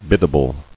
[bid·da·ble]